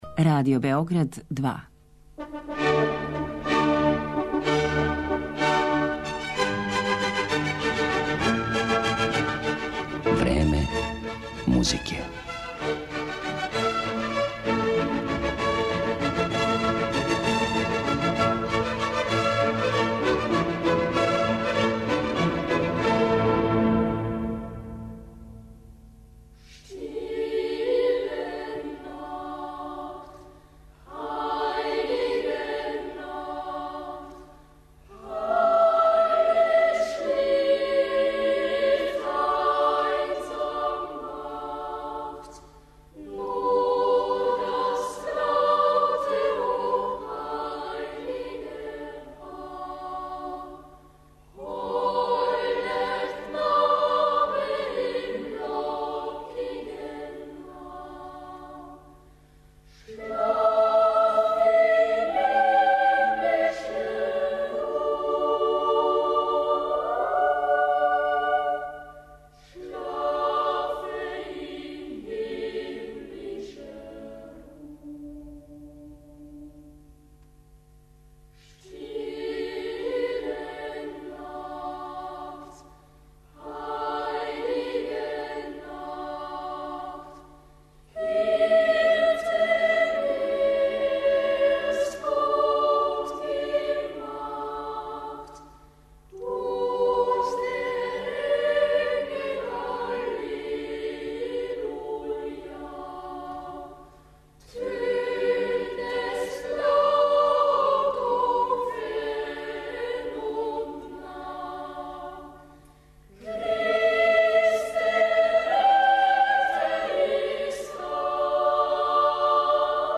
најпопуларнијем дечјем хору на свету
анђеоске гласове